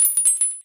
ShiningRinging
bell chime cute ding fairy ring ringing shiny sound effect free sound royalty free Sound Effects